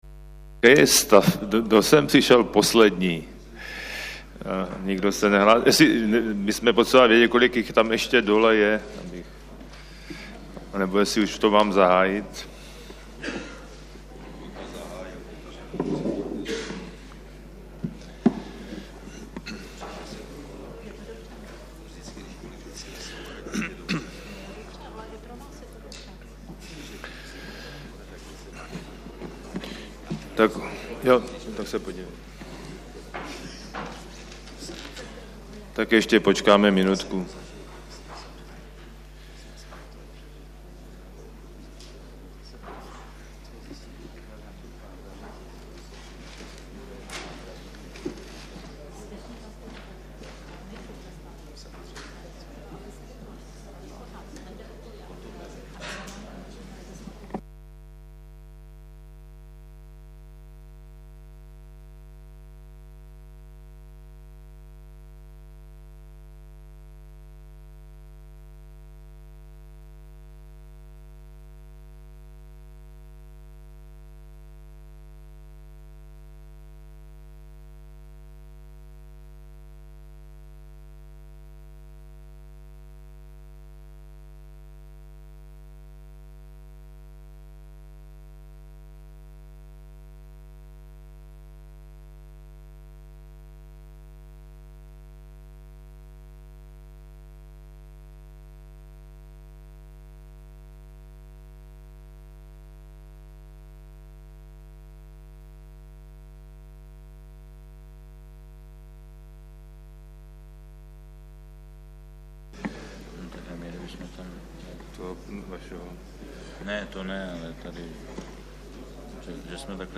� Audio soubor se zvukov�m z�znamem cel�ho semin��e